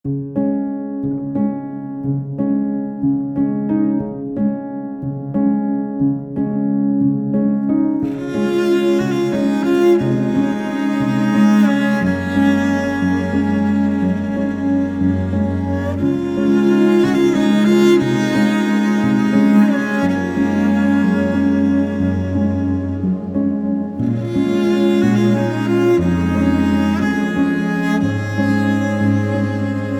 Reflective